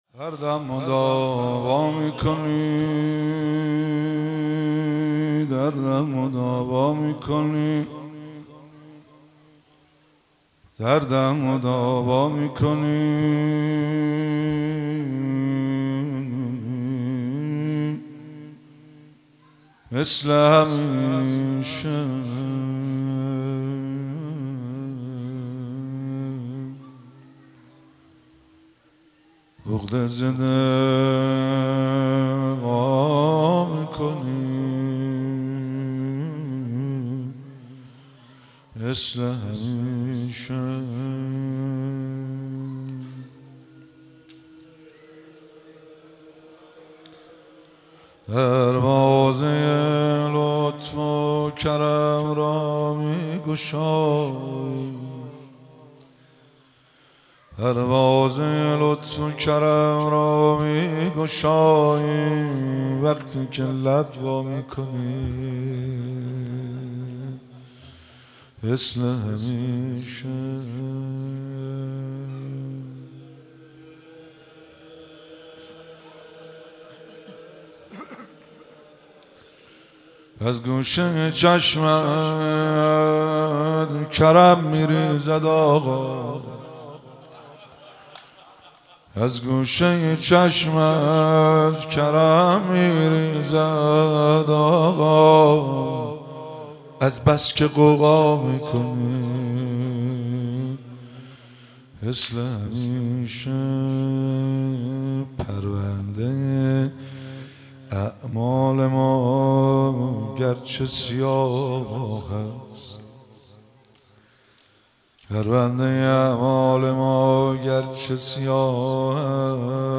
مراسم مناجات خوانی شب هجدهم ماه رمضان 1444
روضه پایانی- دردم مداوا میکنی مثل همیشه